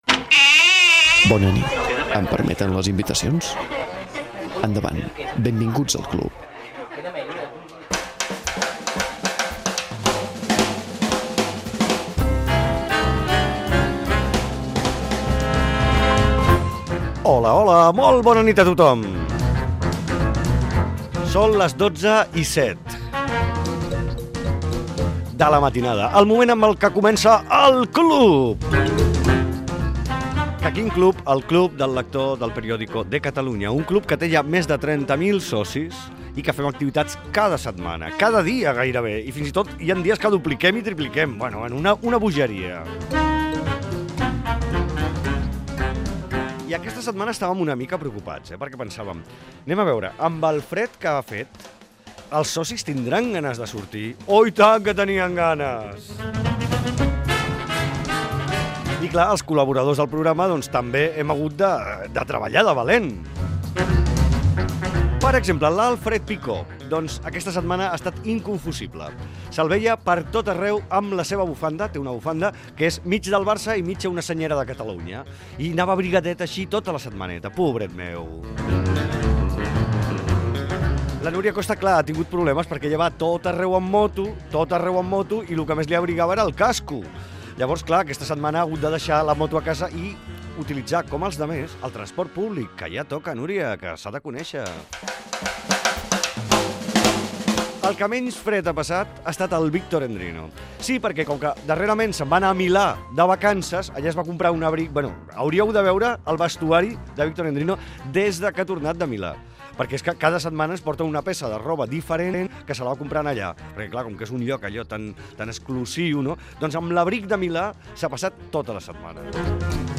Hora, presentació inicial, crèdits del programa tot comentant el fred i identificació del programa
Fragment extret de l'arxiu sonor de COM Ràdio